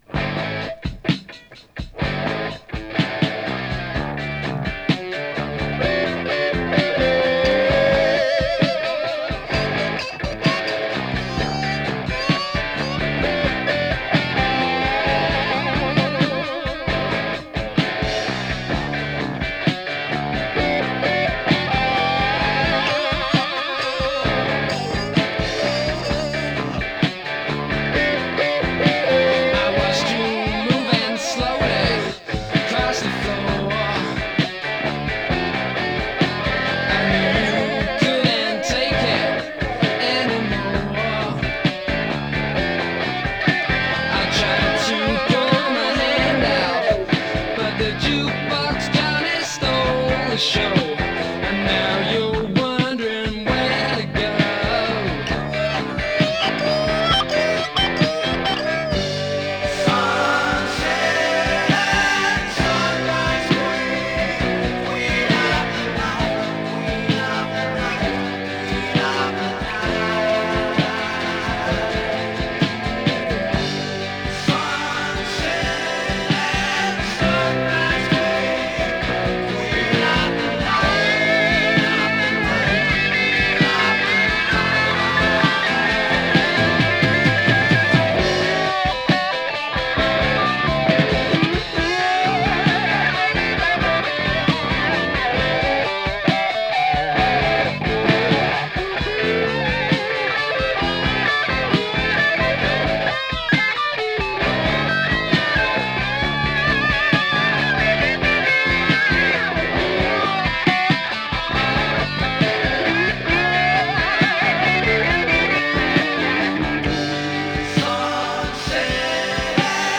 お互いの手の内を知り尽くしたような二人のギターの絡みが絶妙なプログレ・ハードです！